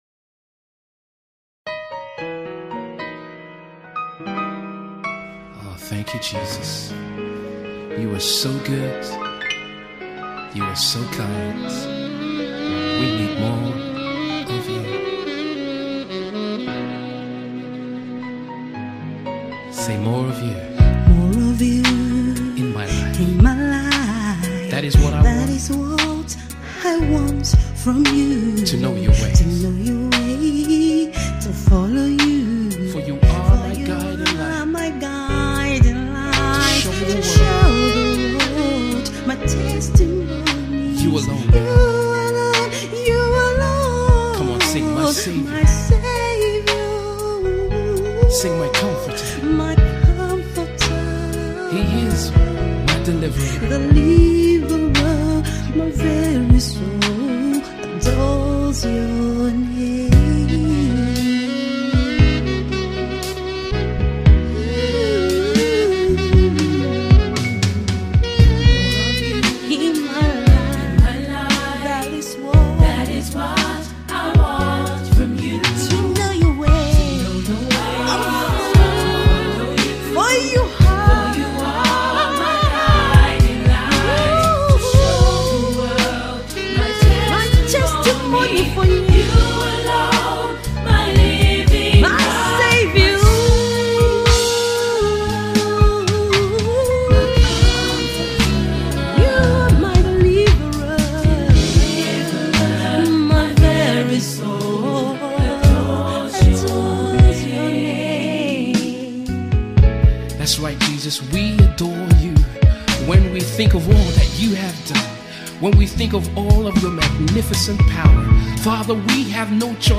Versatile Gospel Music Minister